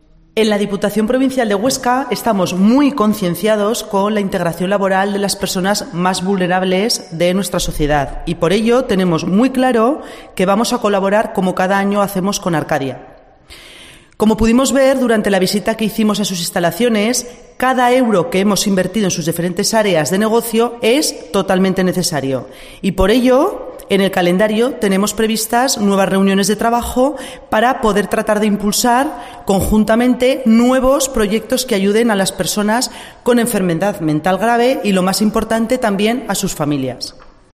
La diputada Lola Ibort destaca la importancia de la inversión que realiza la DPH en Arcadia